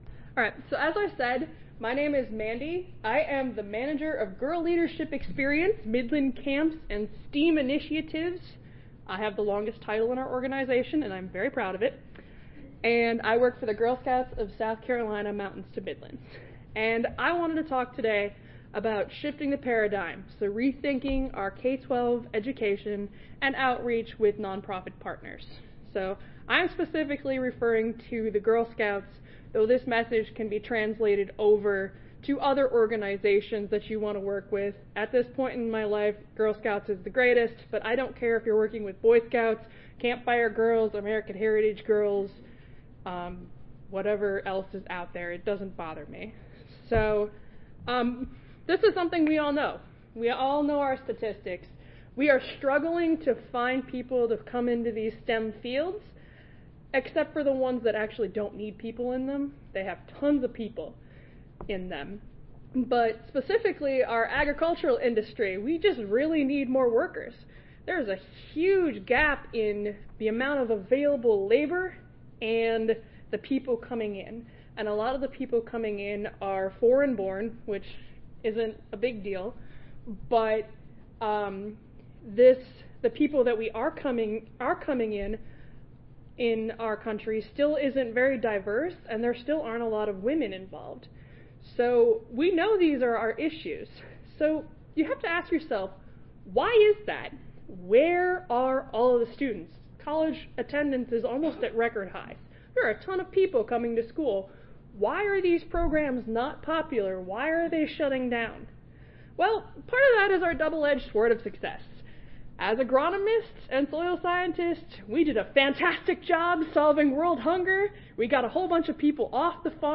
Mountains to Midlands Audio File Recorded Presentation